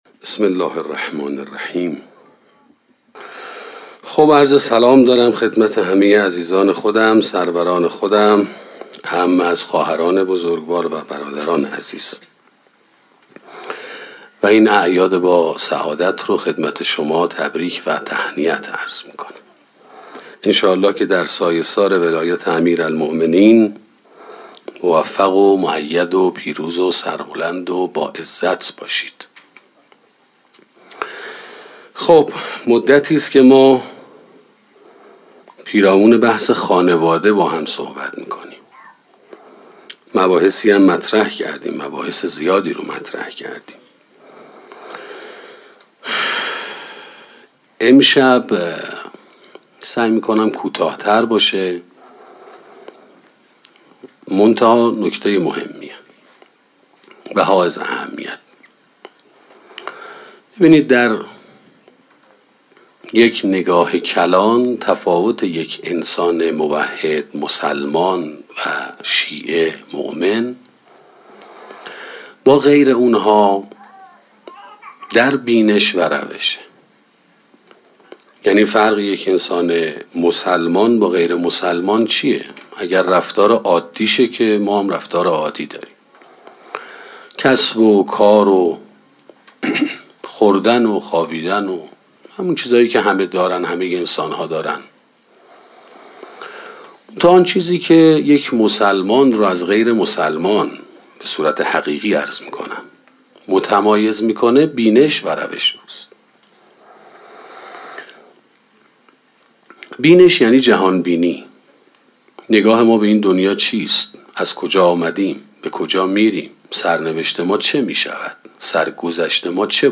سخنرانی خانواده و مقام پدر 2 - موسسه مودت